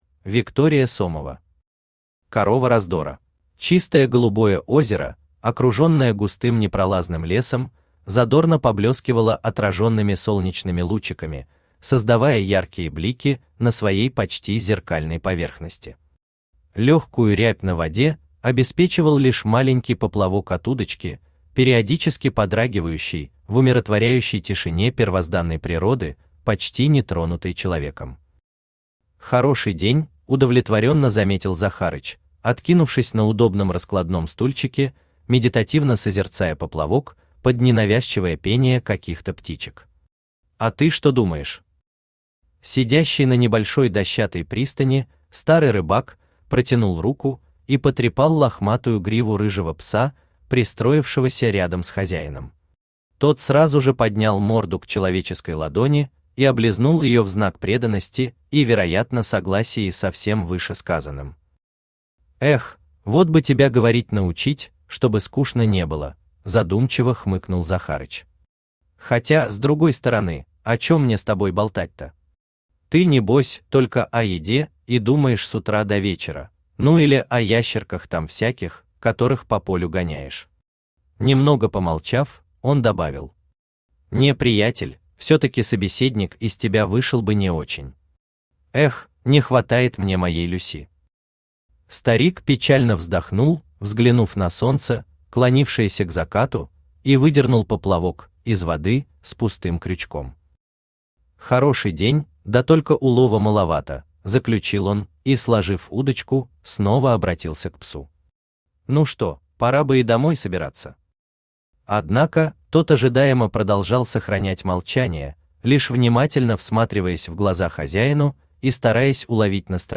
Аудиокнига Корова раздора | Библиотека аудиокниг
Aудиокнига Корова раздора Автор Виктория Сомова Читает аудиокнигу Авточтец ЛитРес.